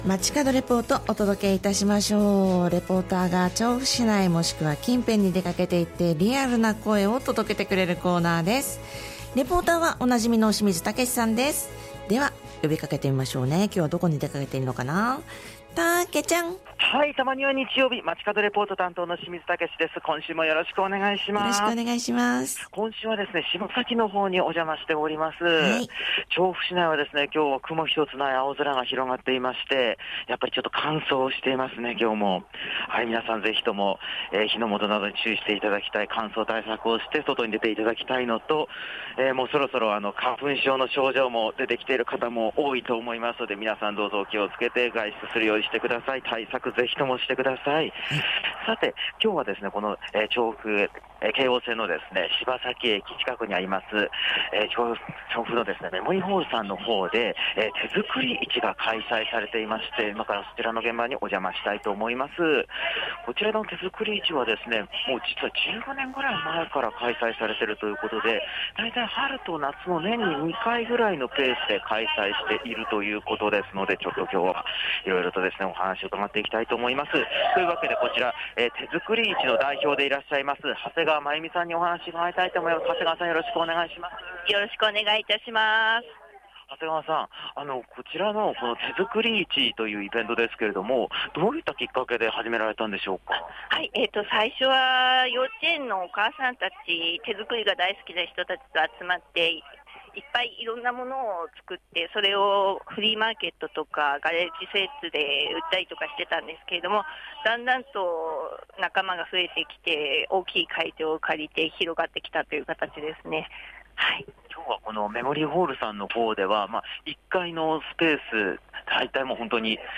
今週も空気の澄んだ青空の下からお届けした本日の街角レポートは、 京王線・柴崎駅から徒歩3分、調布メモリードホールで開催中の 「手作り市」の会場からのレポートです！！